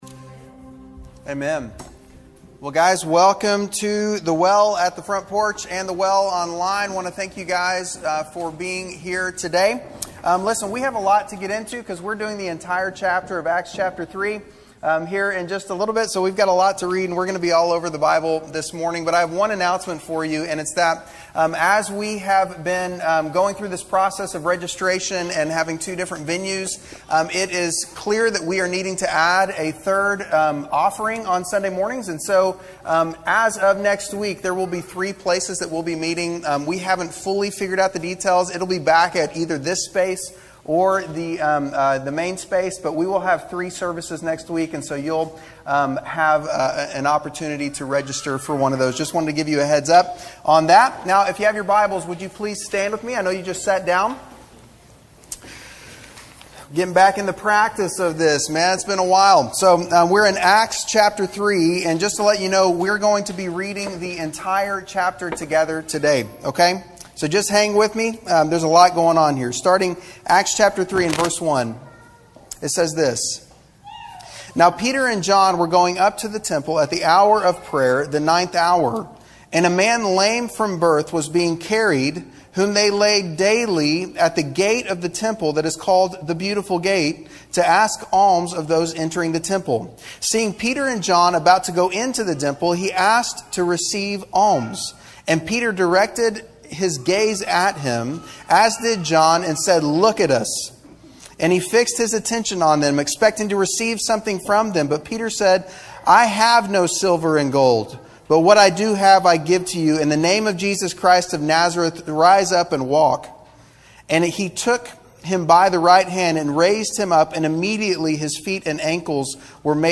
The Well's July 19th Live Worship Gathering_1.mp3